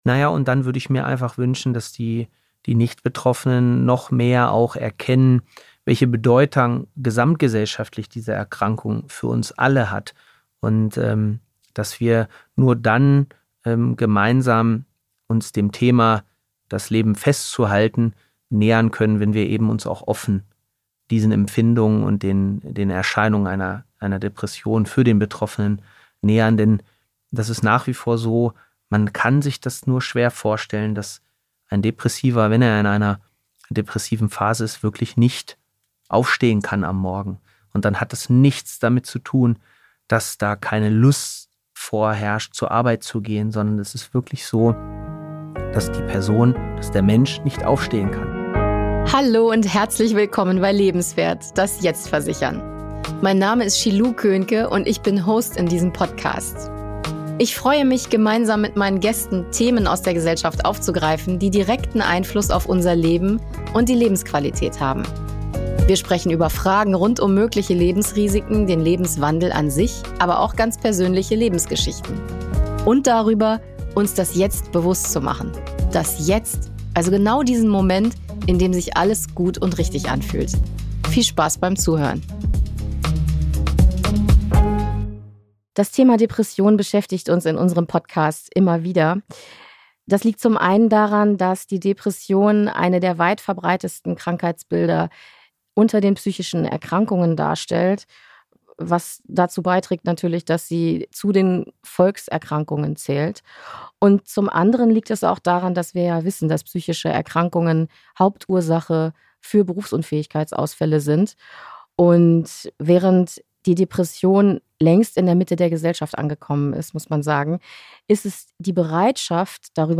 Depressionen - ein Gespräch mit der Robert-Enke-Stiftung ~ LebensWert - das Jetzt versichern Podcast